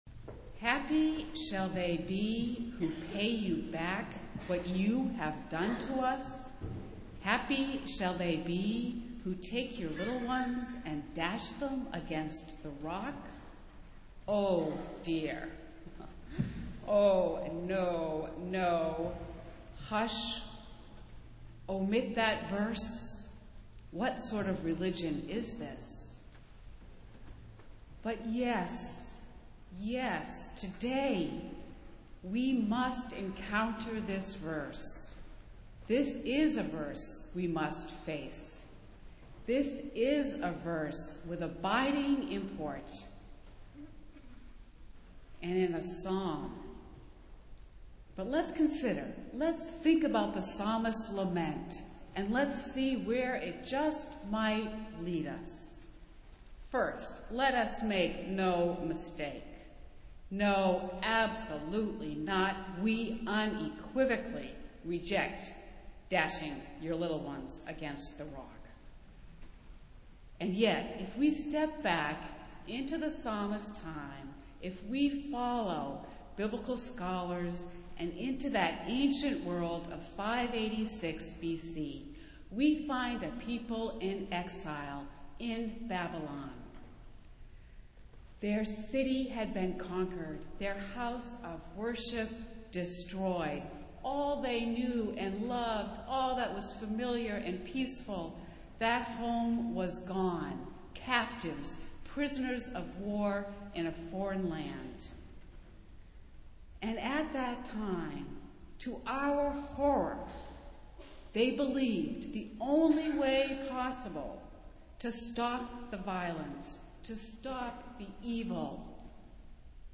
Festival Worship - Fifth Sunday of Easter